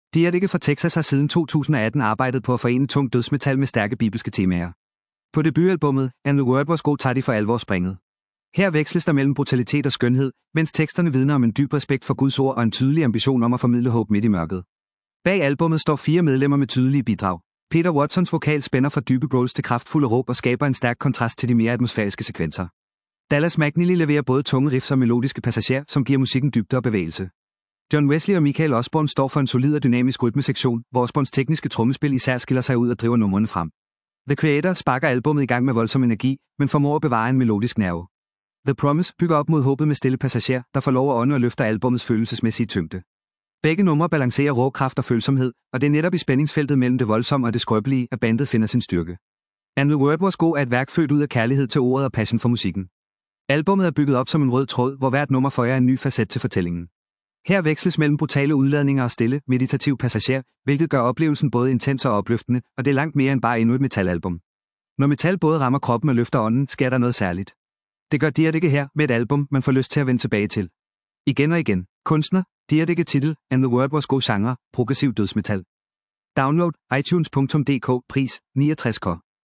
Genre: Progressiv dødsmetal